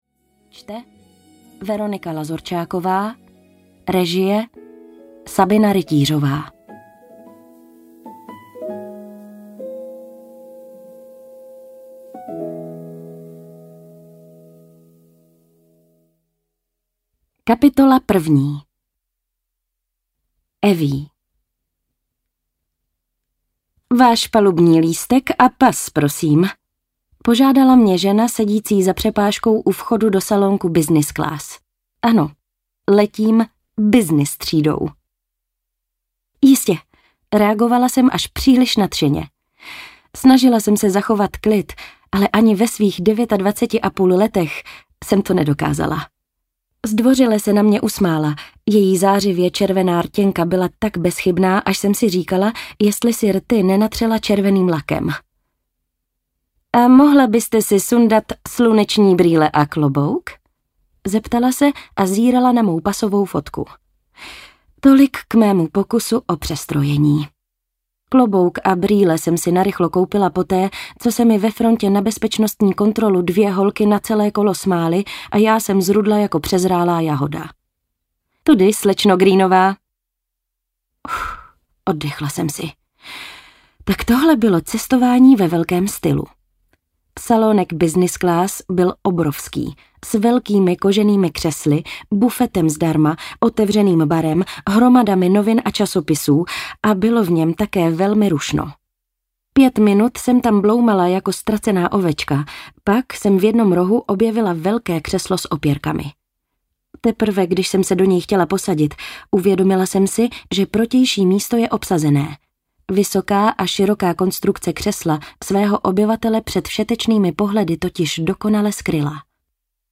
Vánoce na Manhattanu audiokniha
Ukázka z knihy